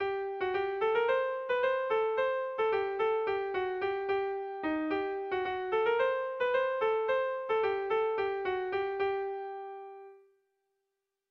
Kontakizunezkoa
ABAB